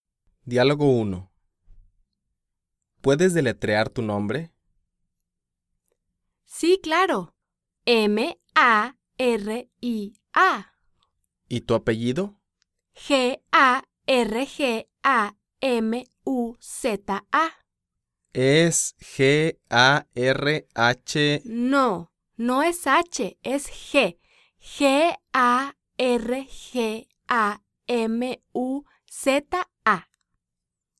Recording: 0005 Level: Beginners Spanish Variety: Spanish from Mexico
Transcribe the whole conversation by writing word by word what the speakers say.